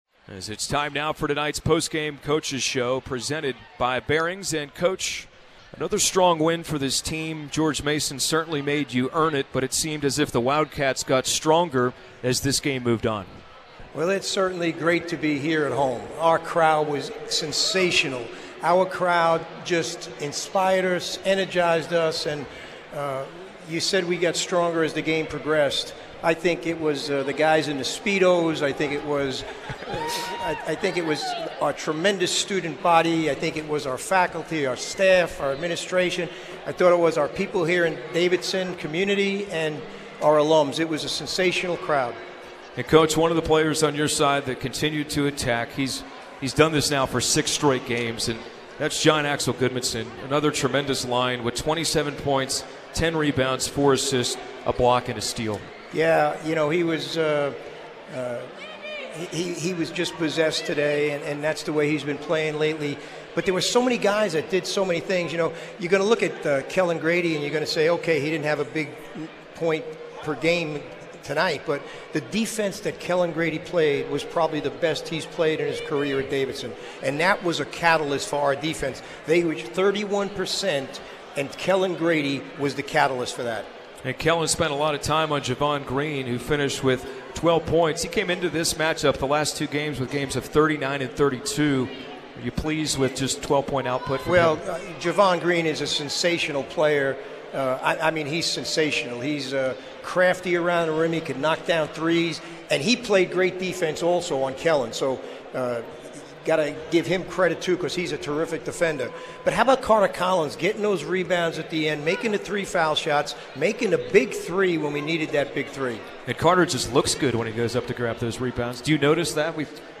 McKillop Postgame Radio Interview